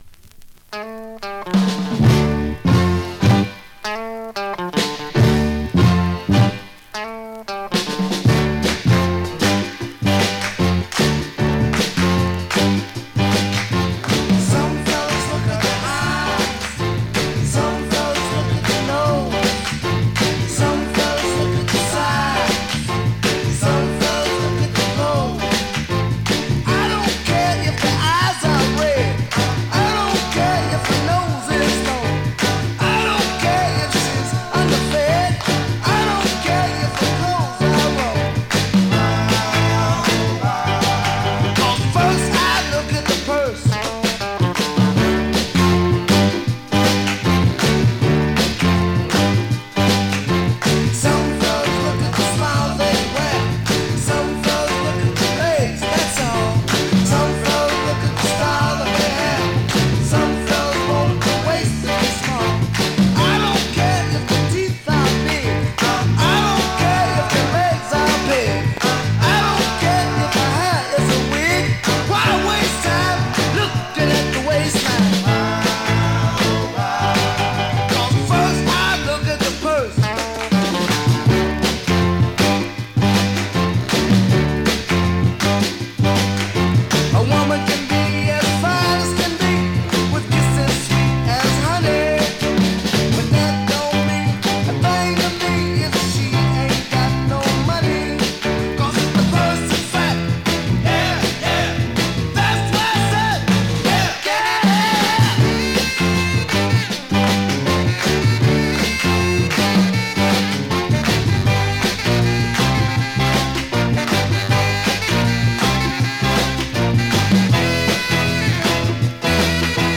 Vinyl has a few light marks plays great , has a drill hole .
Great classic mid-tempo Northern Rnb dancer .